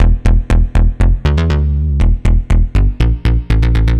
AM_OB-Bass_120-E.wav